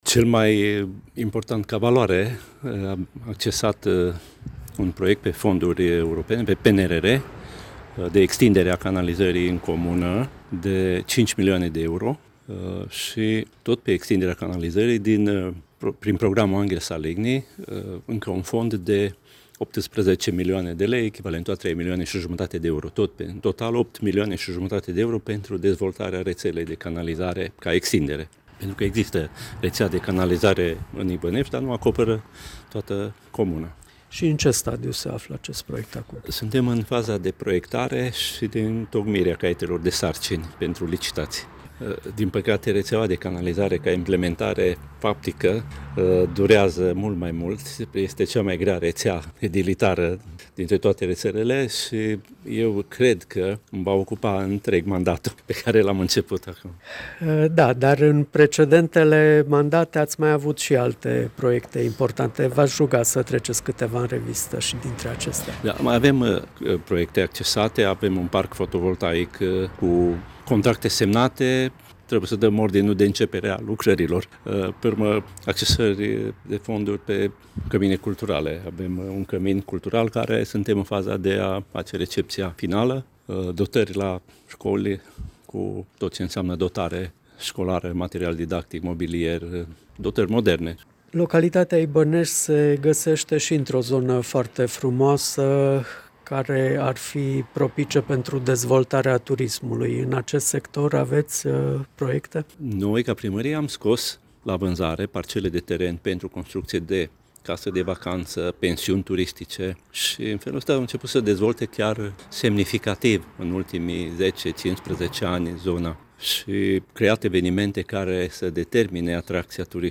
Vă invităm să aflați mai multe de la primarul localității, Dan Vasile Dumitru, care de curând a preluat cel de-al 7 mandat în fruntea comunei: